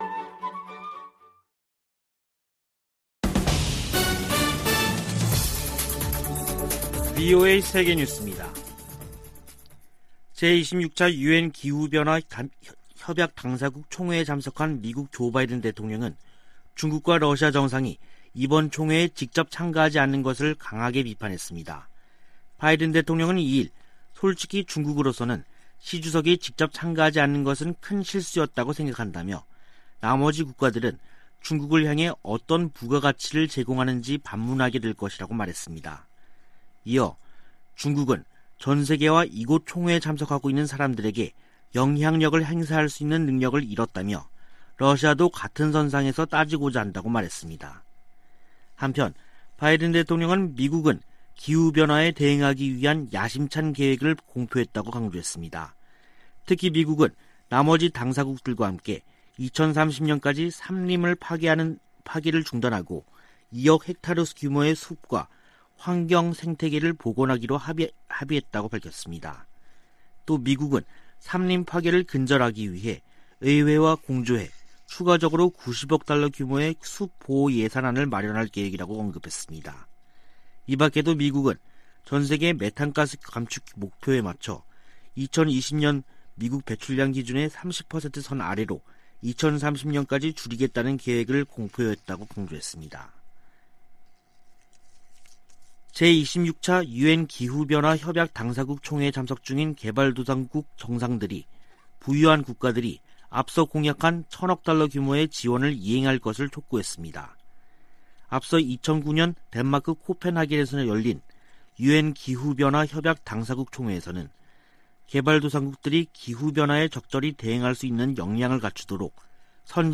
VOA 한국어 간판 뉴스 프로그램 '뉴스 투데이', 2021년 11월 3일 2부 방송입니다. 미 연방법원이 중국 기업과 관계자들의 대북제재 위반 자금에 대해 몰수 판결을 내렸습니다. 중국과 러시아가 유엔 안보리에 다시 대북제재 완화 결의안을 제출한 것은 미국과 한국의 틈을 벌리기 위한 것이라고 미국 전문가들이 분석했습니다.